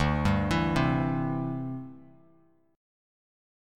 D9sus4 chord